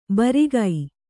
♪ barigai